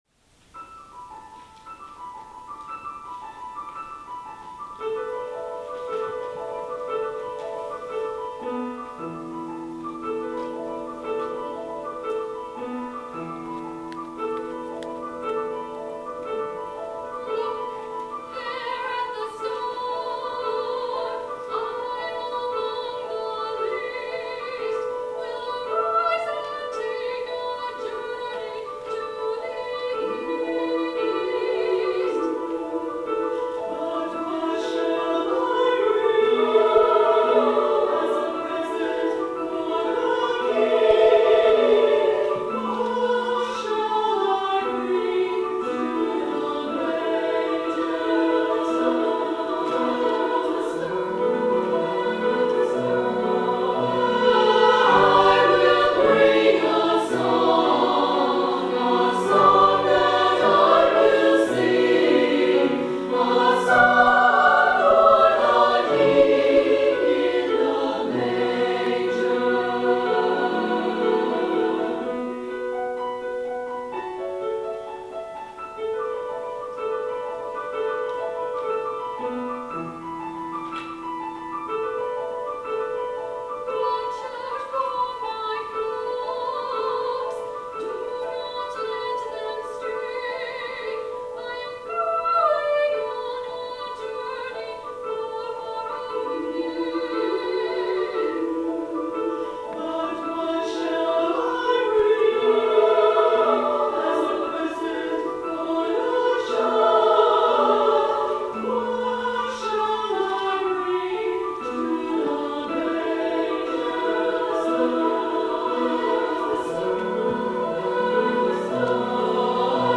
for SSA Chorus, Soprano Solo, and Piano (2008)
SSA piano version